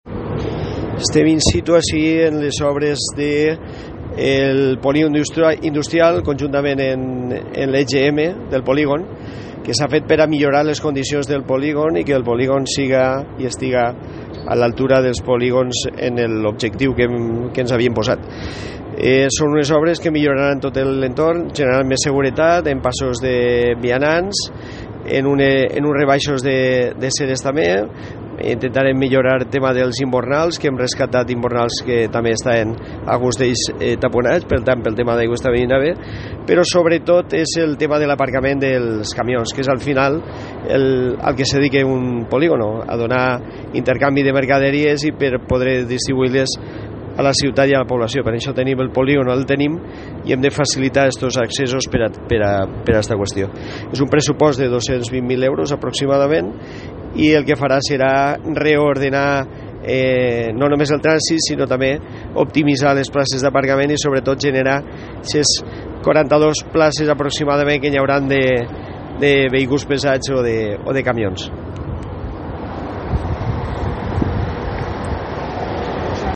Escolta a José Antonio Redorat, regidor d’urbanisme de Benicarló